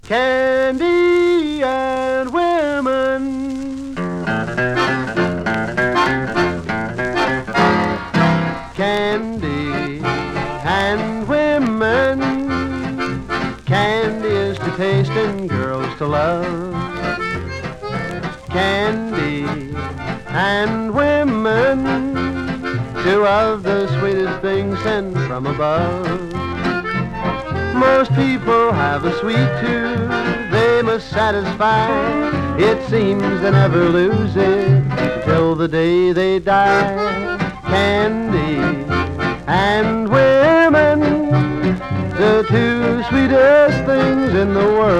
Country, Hillbilly　UK　12inchレコード　33rpm　Mono